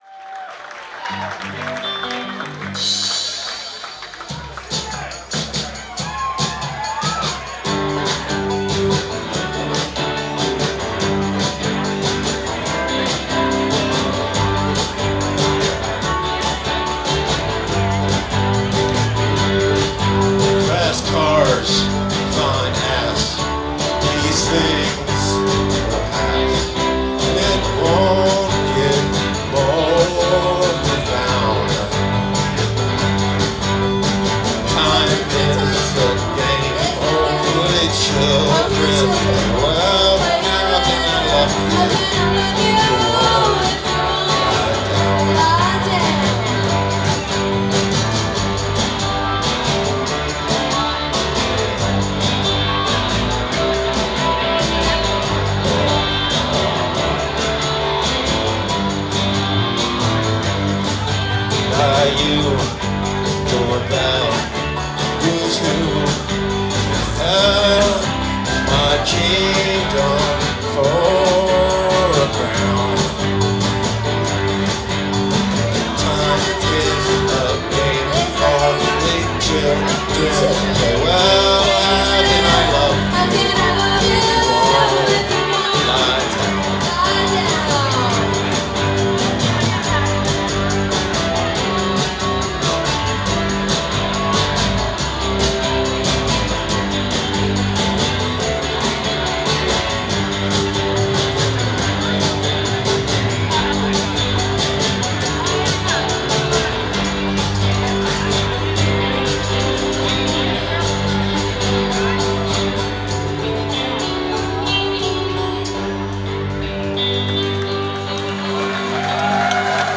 Live at The Middle East Downstairs